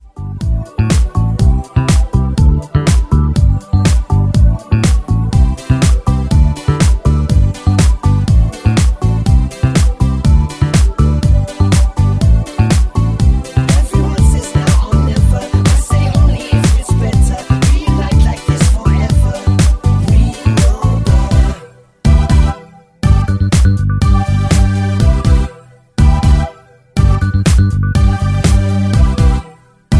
D
MPEG 1 Layer 3 (Stereo)
Backing track Karaoke
Pop, 2010s